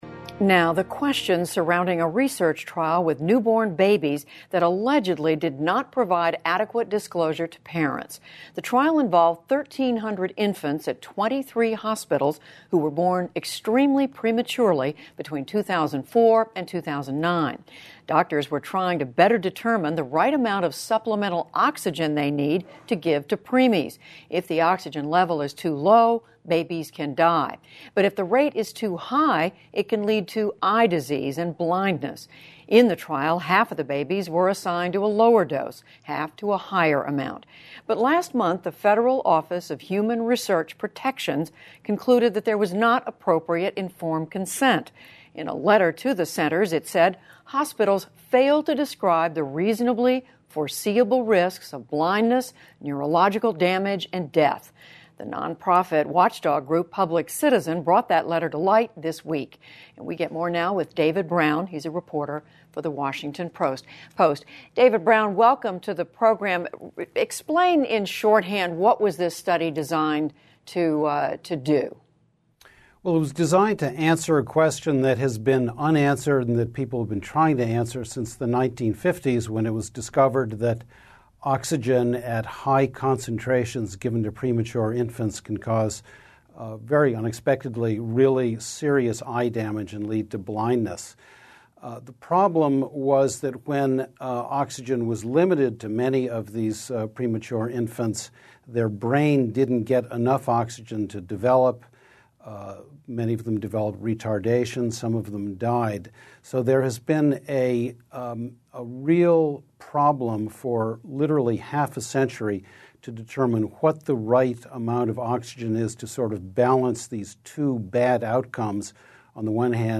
英语访谈节目:院方因未透漏早产儿临床研究的风险而遭起诉